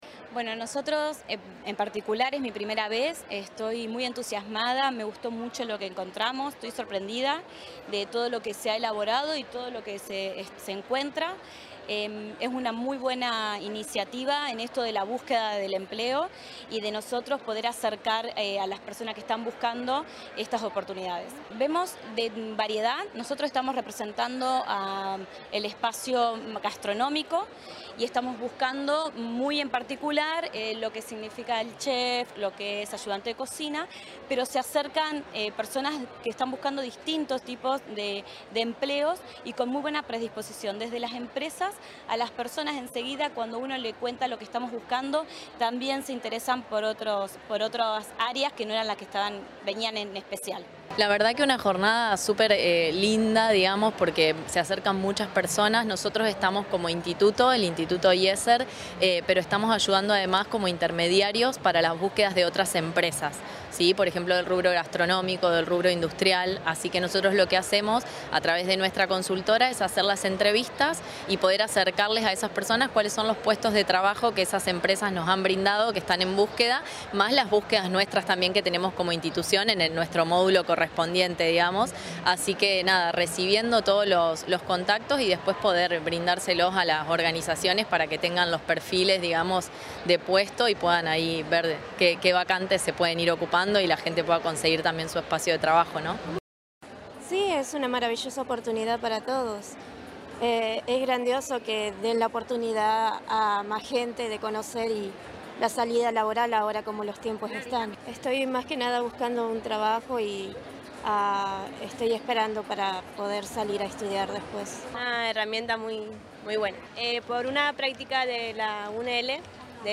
Testimonios de interesados y empresarios